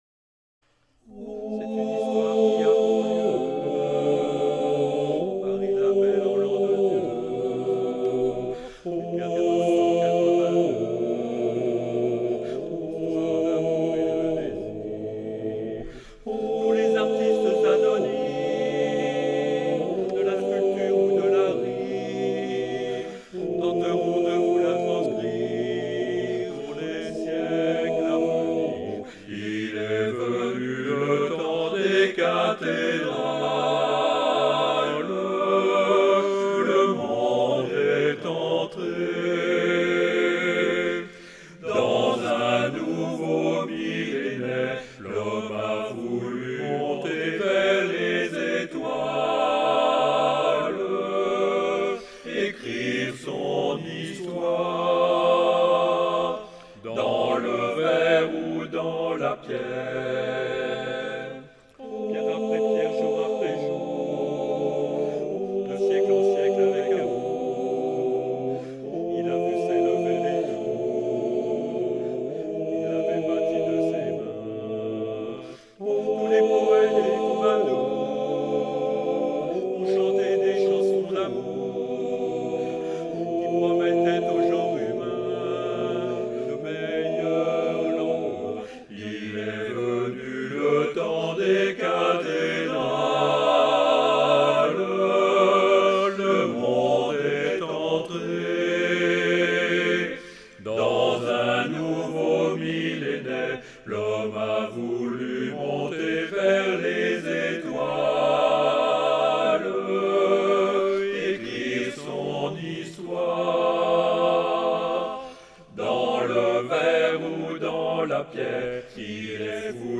Basse
à 4 voix